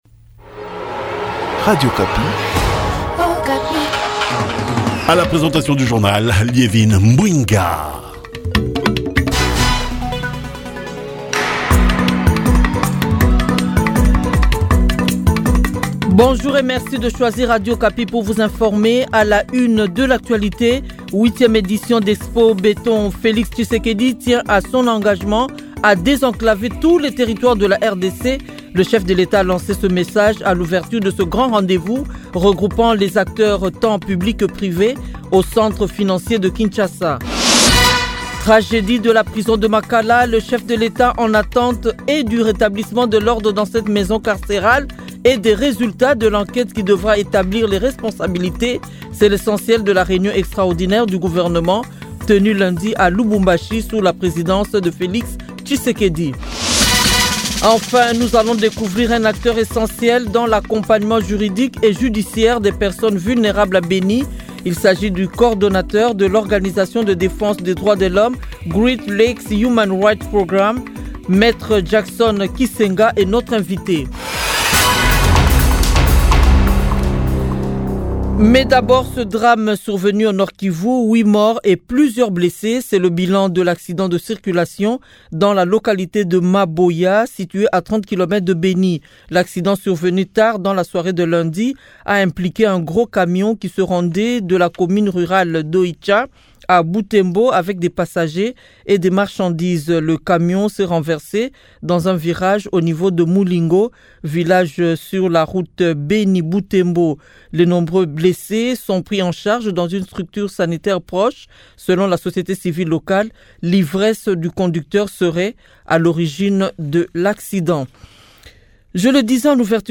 Le Journal en Francais Matin